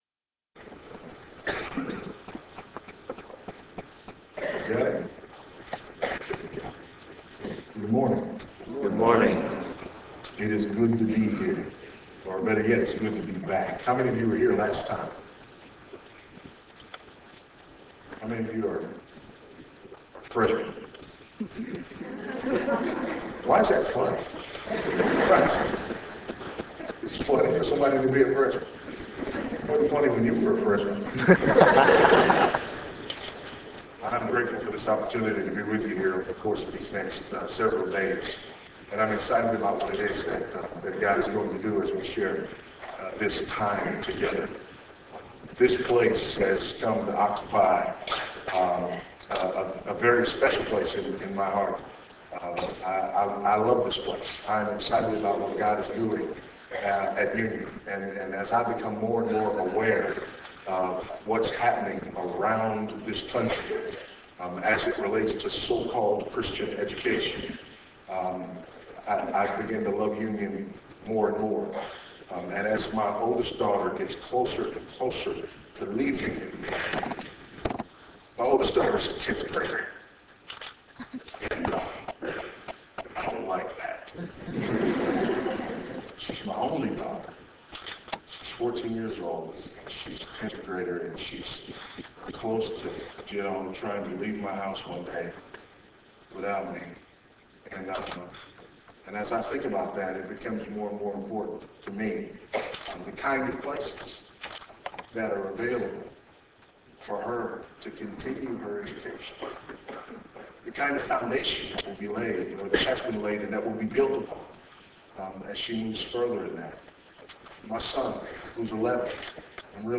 Faith in Practice Conference Session 1